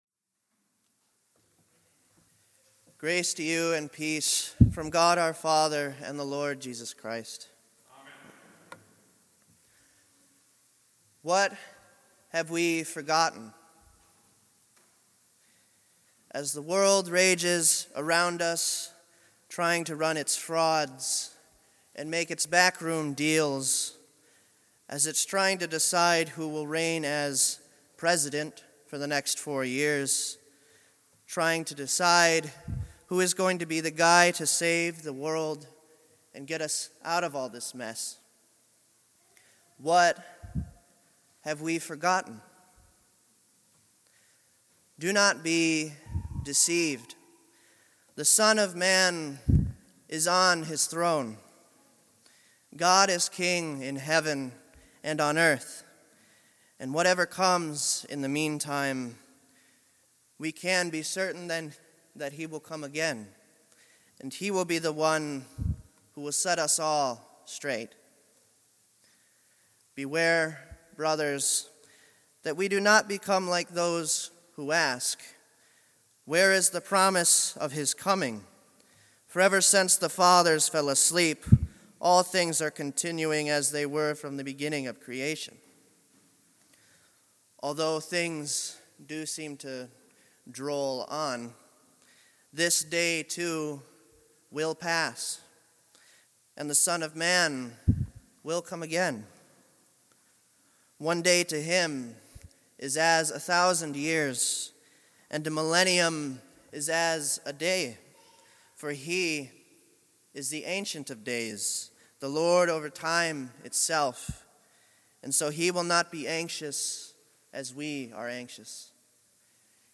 Second-Last Sunday of the Church Year